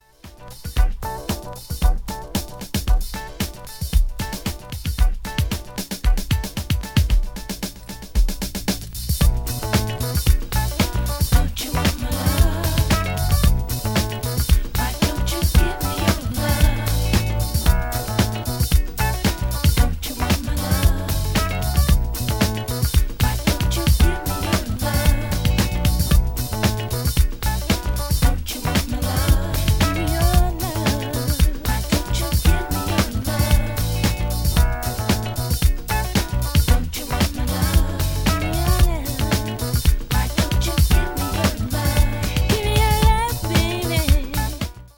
grooving, soulful disco anthem